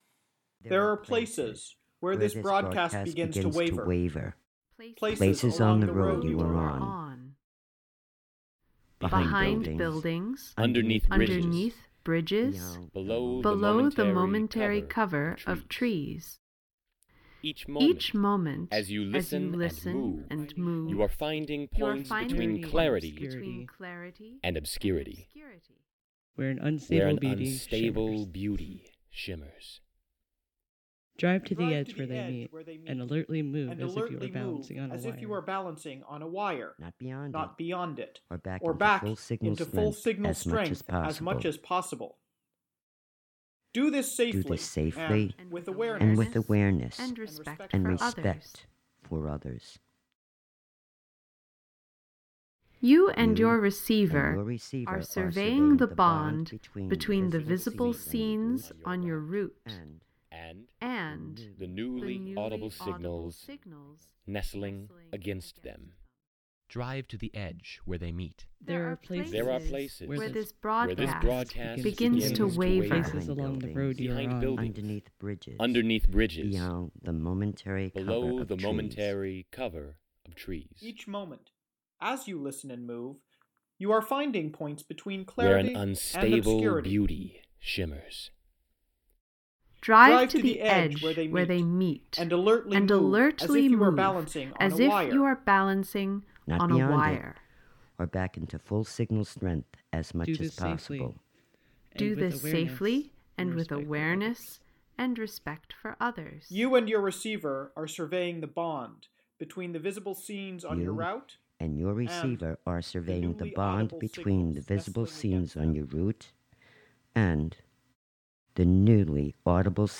Drivers and passengers, as well as listeners at home, follow the intermittent instructions given as each movement happens: firstly, by exploring within an area where the transmission’s frequency is somehow unstable or weak, and secondly, by moving around in that space, this edge of clarity, inspecting different interferences and interruptions.